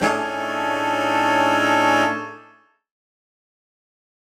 UC_HornSwellAlt_Bmaj7b5.wav